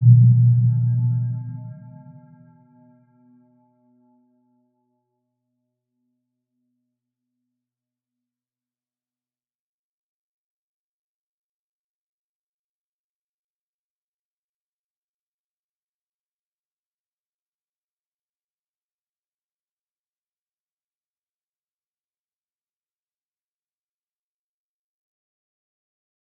Round-Bell-B2-mf.wav